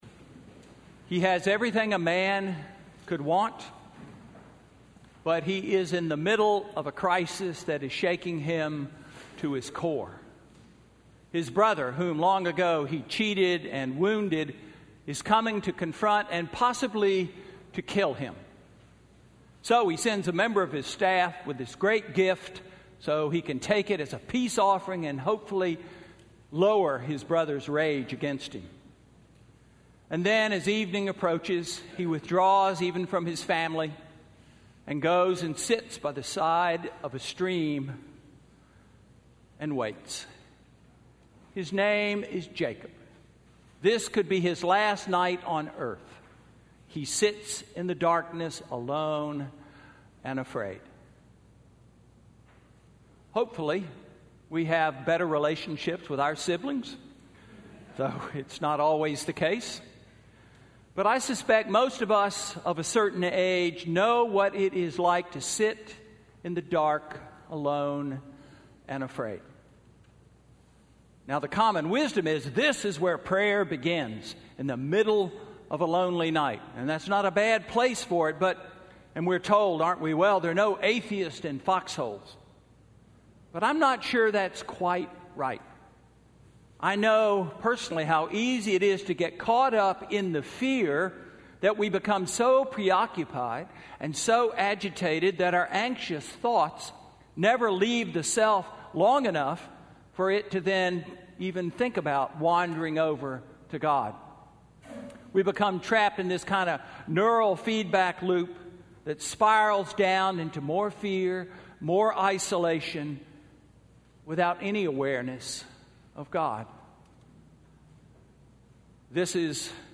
Sermon–October 16, 2016